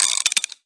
Media:RA_Colt_Evo.wav UI音效 RA 在角色详情页面点击初级、经典和高手形态选项卡触发的音效